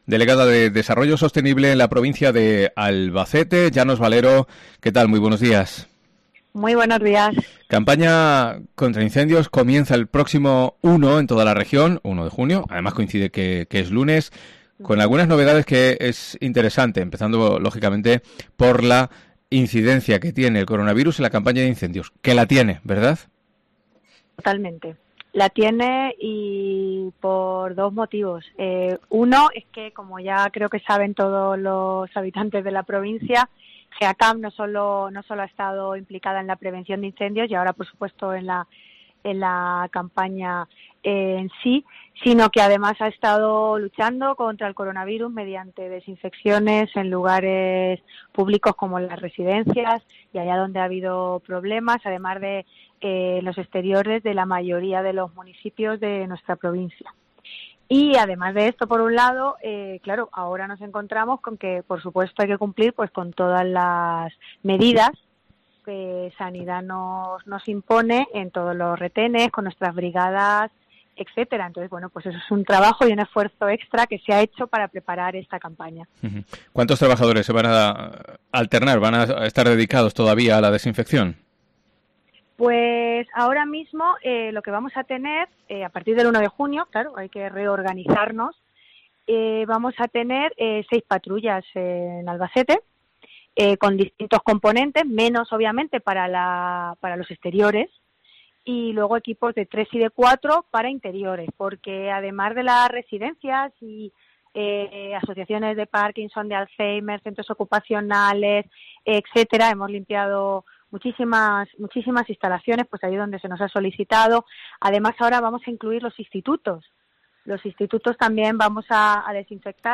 ENTREVISTA COPE
La deleggada de Desarrollo Sostenible, Llanos Valero, nos ofrece todos los detalles